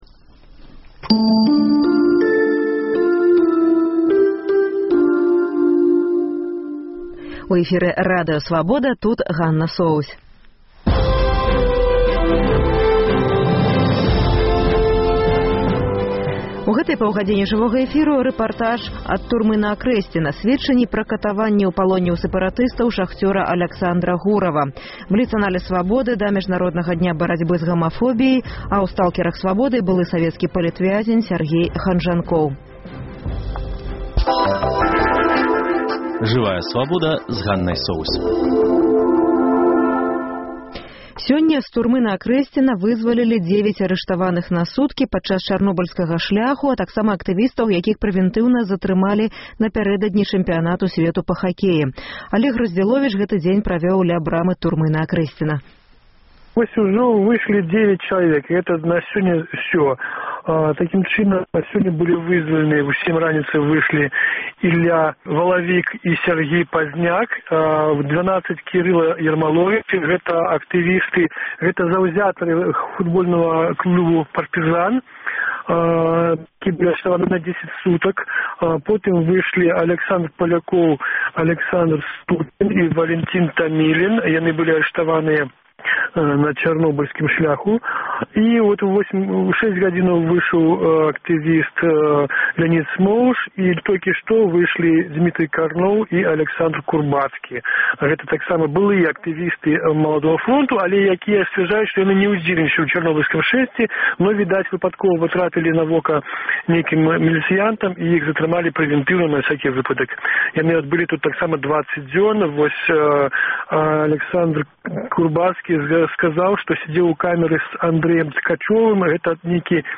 Жывое ўключэньне Ці гатовы Эўразьвяз увесьці эканамічныя санкцыі супраць Расеі?